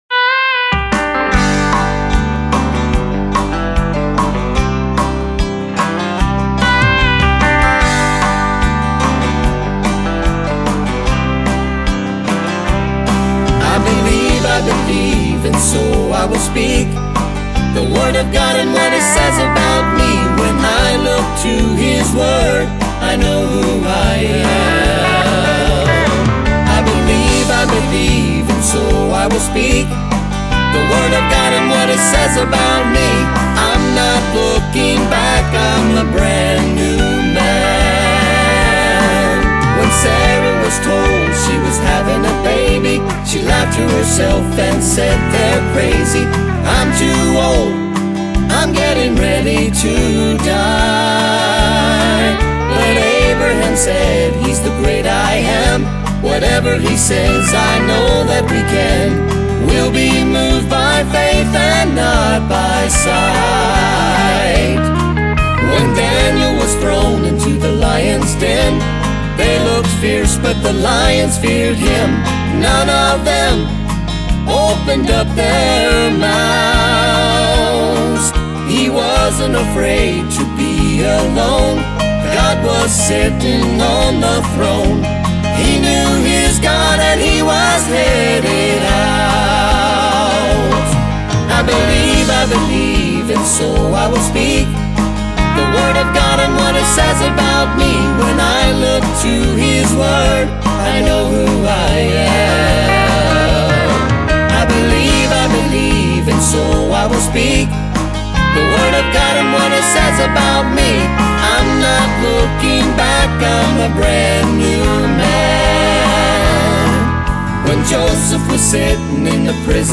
Southern Gospel Songwriter
at Chapel Valley Studio.